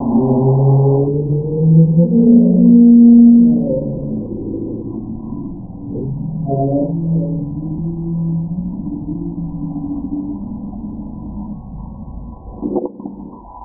Acoustic monitoring devices have been moored in a number of coastal locations around the South Island in order to track numbers, locations and movements of our marine mammal populations.
Southern Right Whale recording
right_whale1.wav